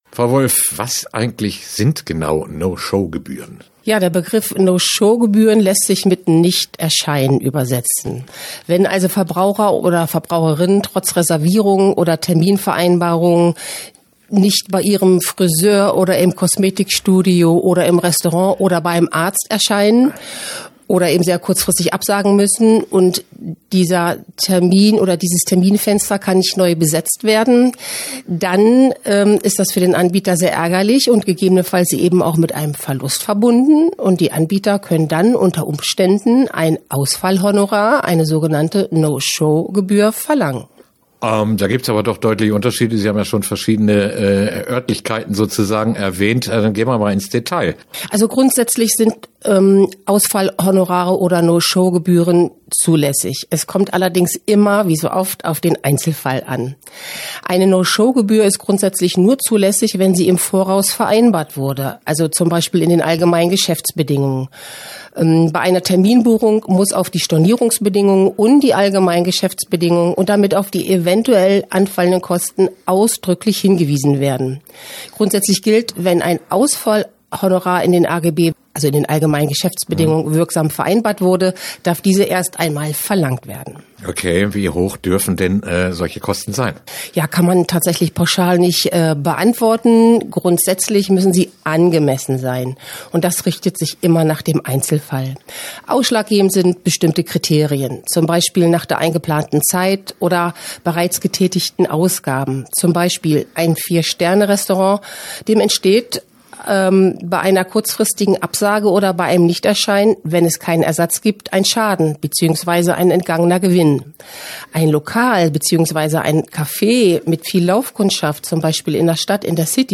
Interview-No-Show-Gebuehren_pmm.mp3